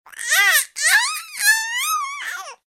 babyscream3.ogg